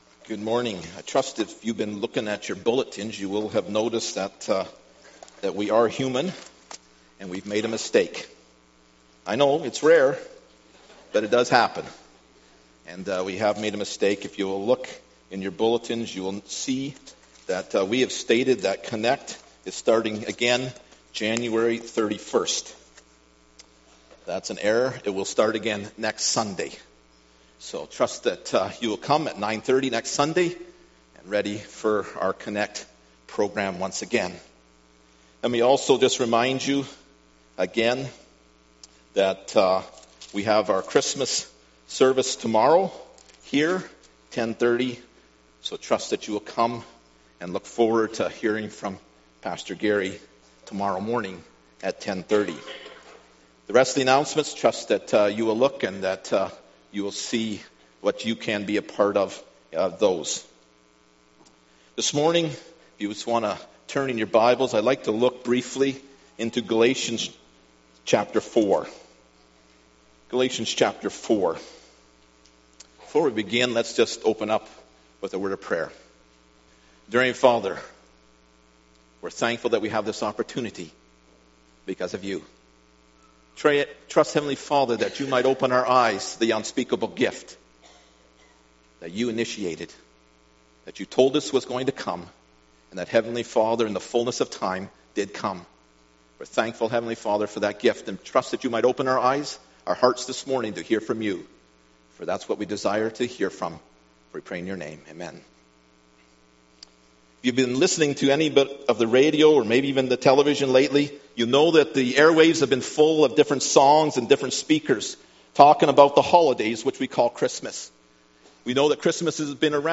Passage: Galatians 4:1-7 Service Type: Sunday Morning